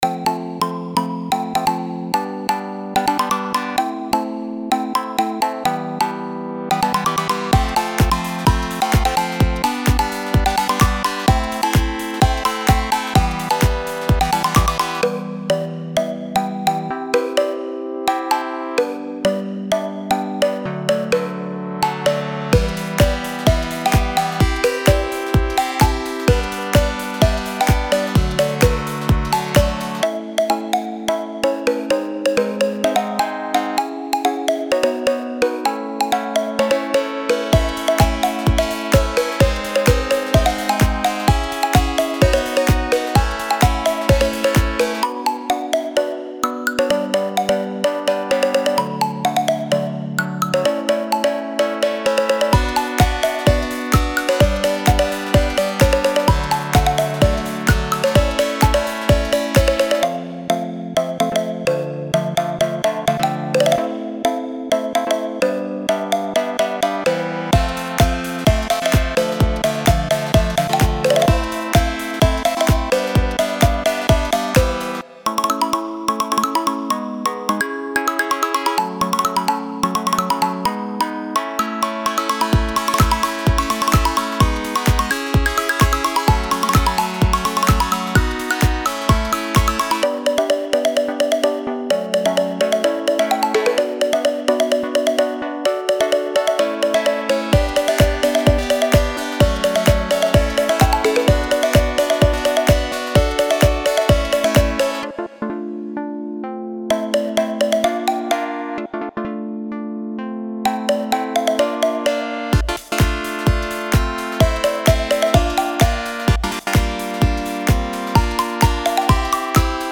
پیشنمایش صوتی
دسته بندی مطلب میدی کیت
در گام های مختلف